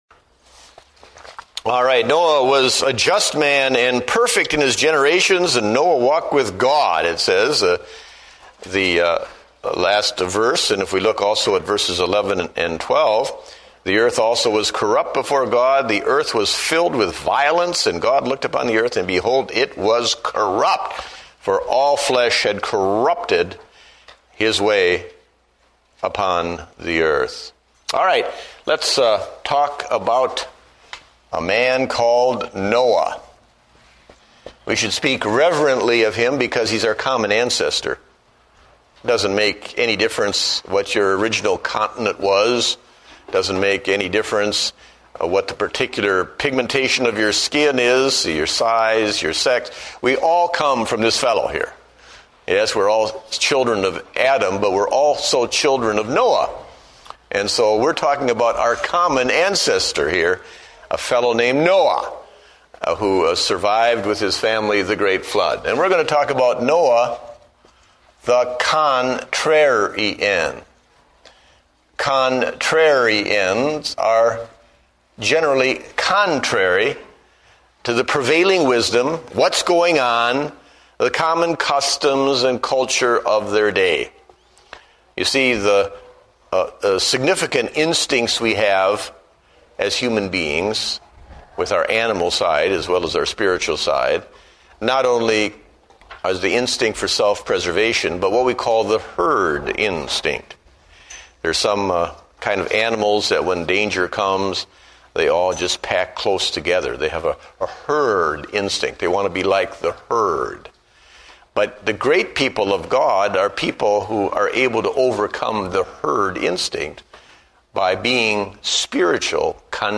Date: January 18, 2009 (Adult Sunday School)